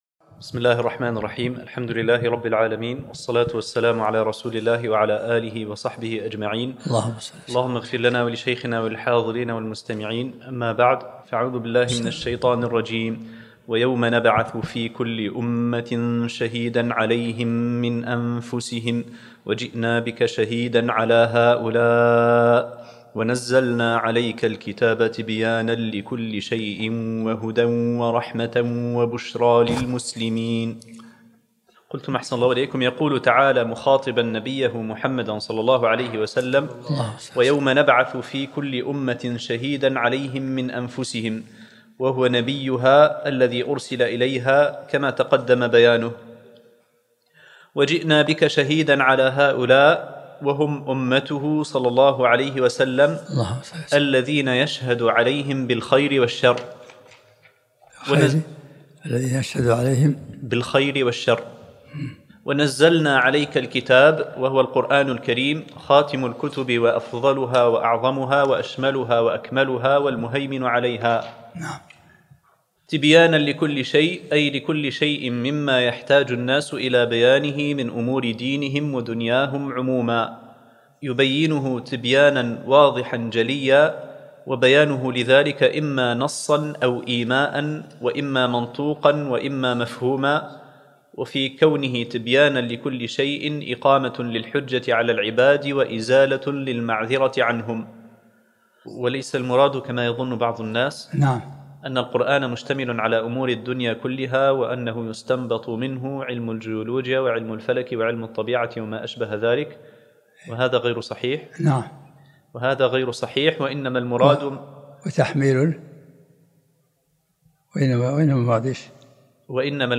الدرس التاسع من سورة النحل